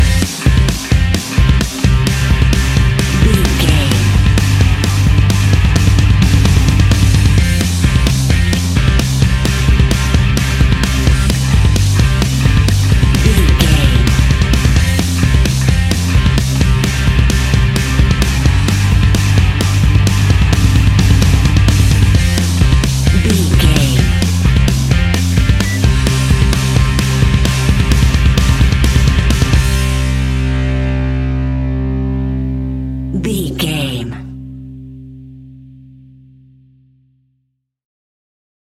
Ionian/Major
hard rock
distortion
punk metal
instrumentals
Rock Bass
heavy drums
distorted guitars
hammond organ